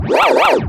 scrath.mp3